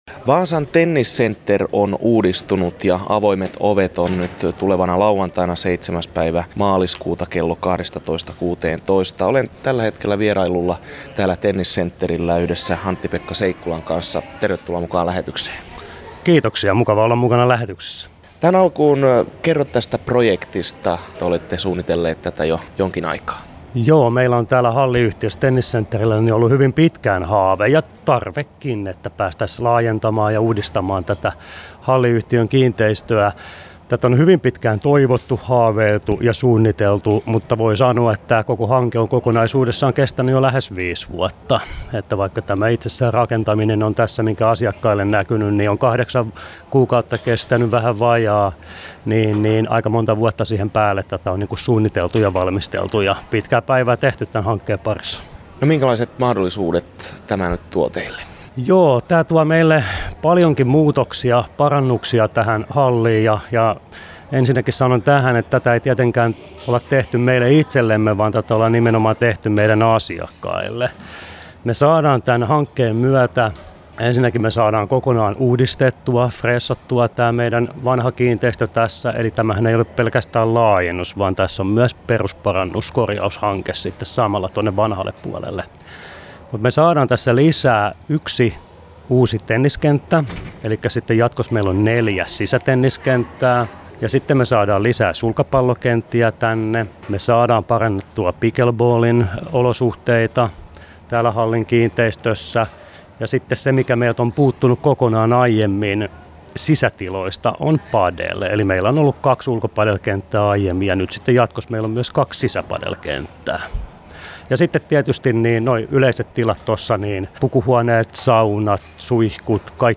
haastatelussa